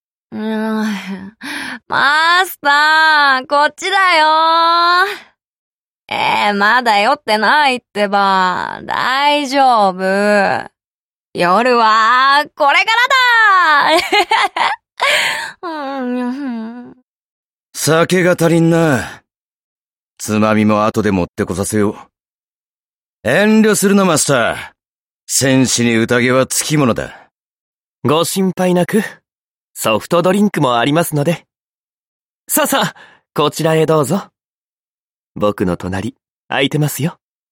声优 花守由美里&三上哲&前野智昭